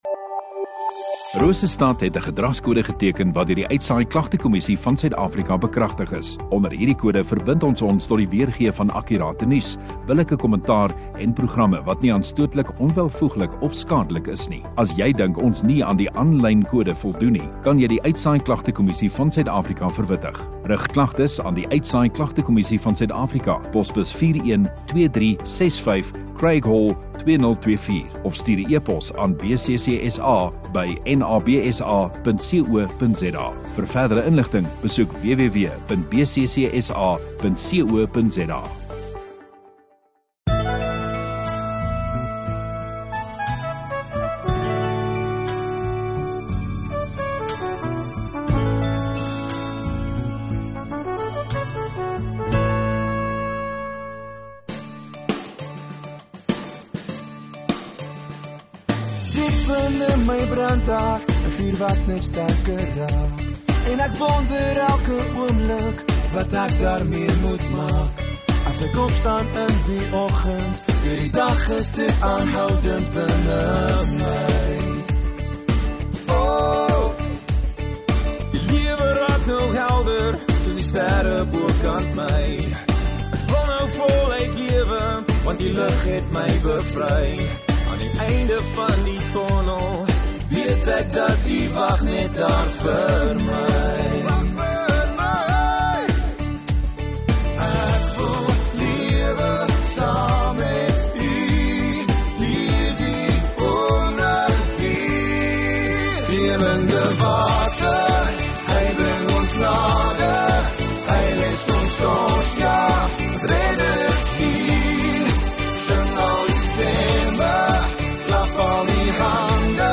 15 Aug Vrydag Oggenddiens